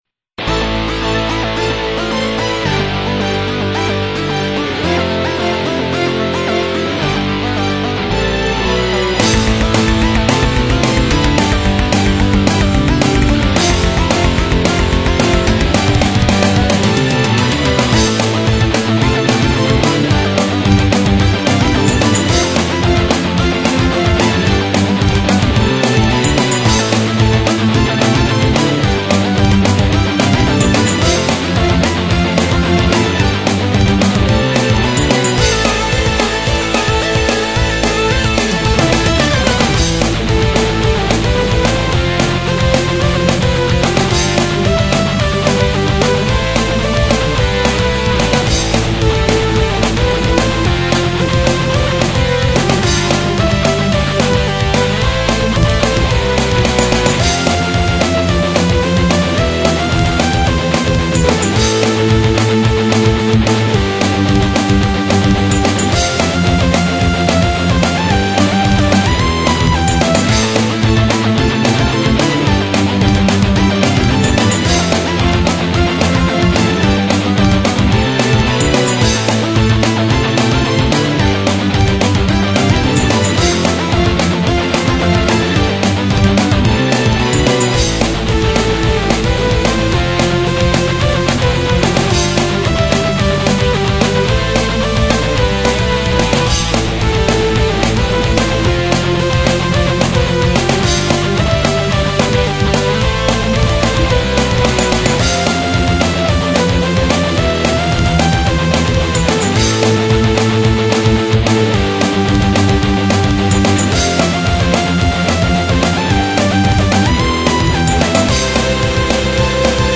イントロのシンセリフが特徴的なインスト曲。
ギターのリフが結構面倒だった。